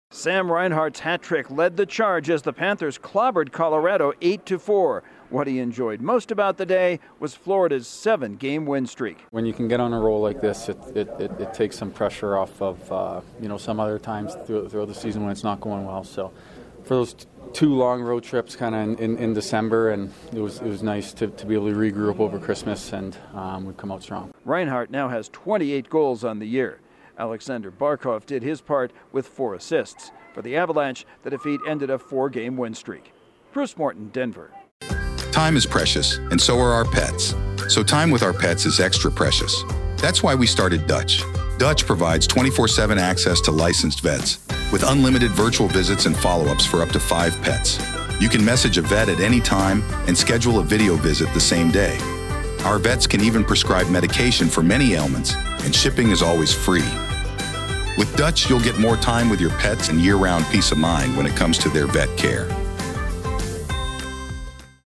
The Panthers continue to own the NHL's longest current winning streak. Correspondent